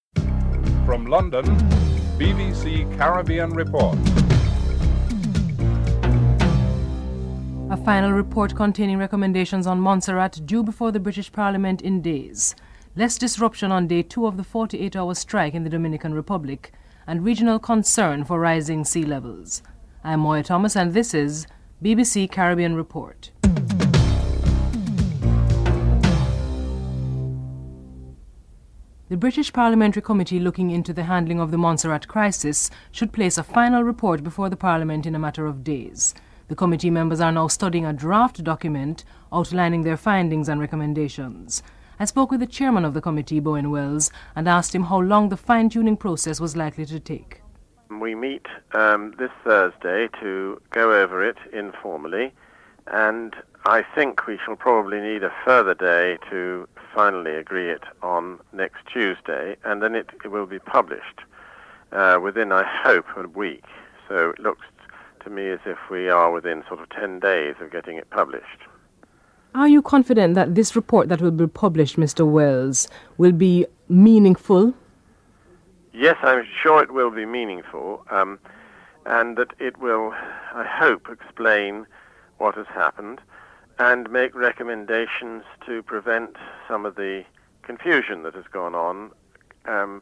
1. Headlines (00:00-00:28)
2. A final report containing recommendations on Montserrat is due before parliament today. Chairman of the Committee, Bowen Wells and Clare Short, Overseas Development Minister are interviewed (00:29-05:05)